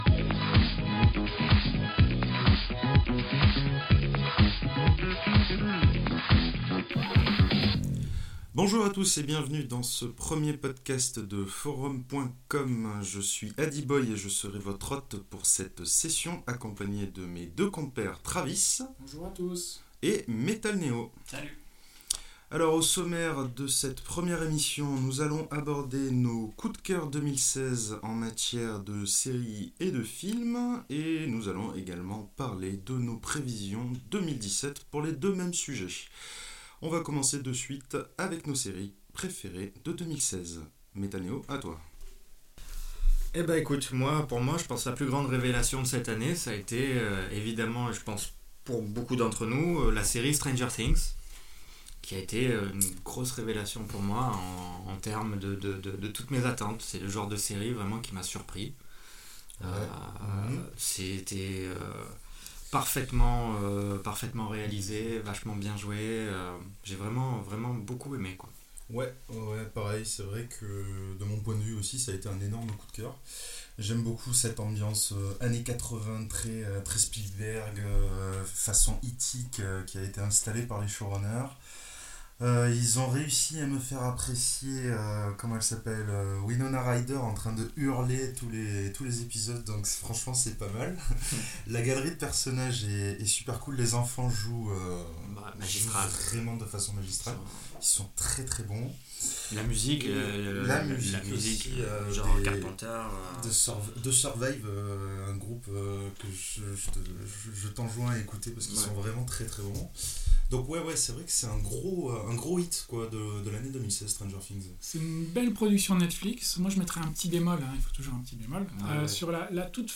Stranger Things Je ne vous cache pas que ce premier podcast est écoutable mais largement améliorable en termes de son. Nous avons eu quelques difficultés et nous en excusons.